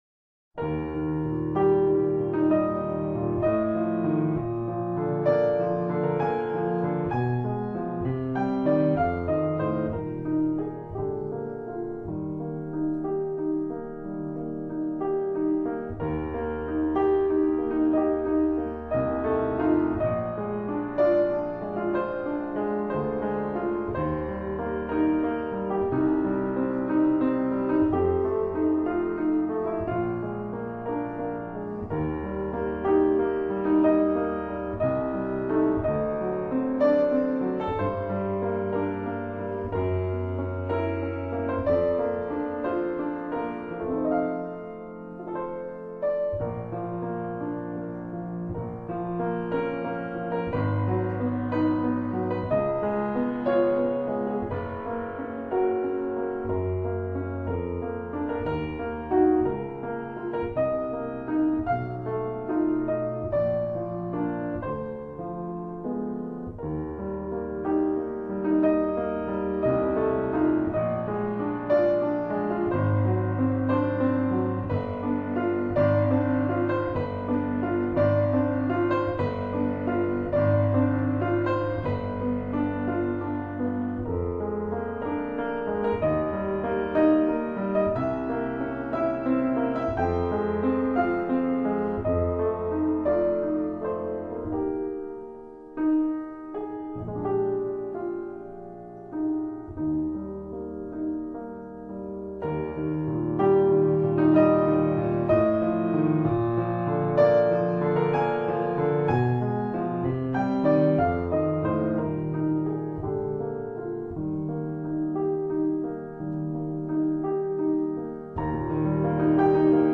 piano
A beautiful piano piece, played wonderfully.